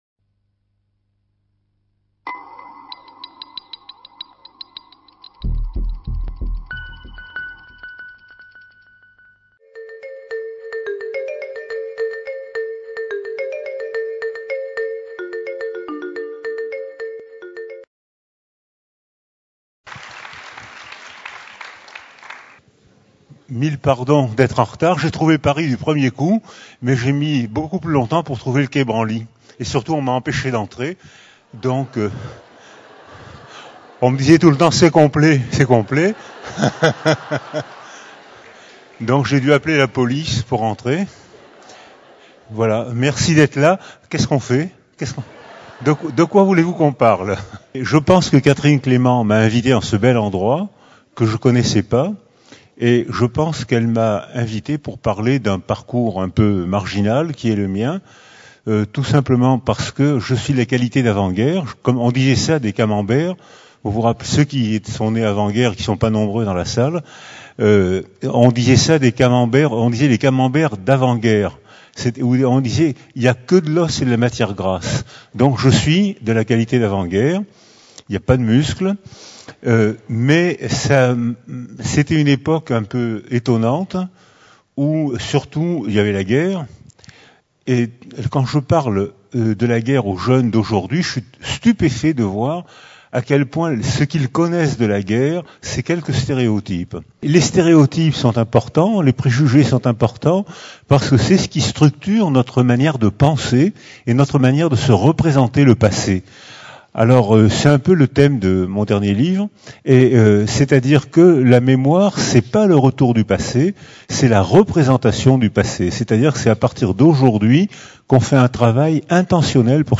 Conférence de l’Université populaire du quai Branly (UPQB), donnée le 31 janvier 2014.